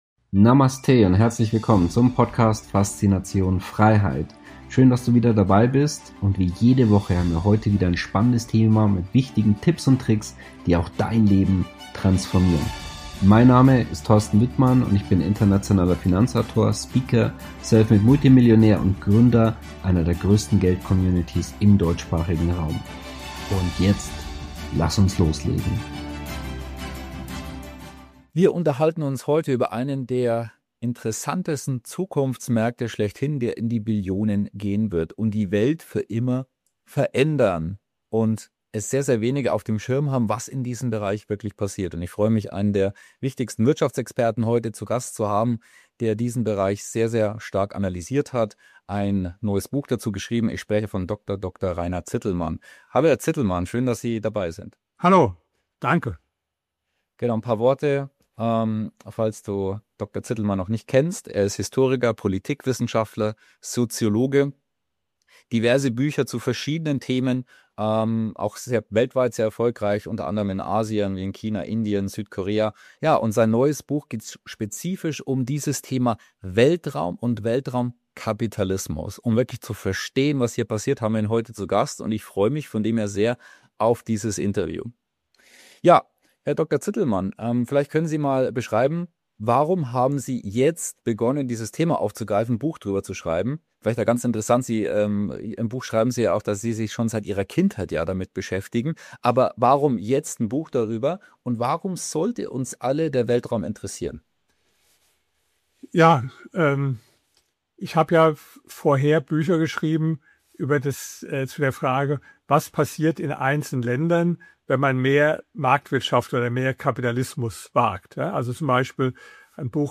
In der heutigen Tipp-Ausgabe habe ich Historiker, Politikwissenschaftler, Soziologen und Bestsellerautor Dr. Dr. Rainer Zitelmann zu Gast.